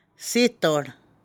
Stress falls on the initial syllable of the root word.
ssee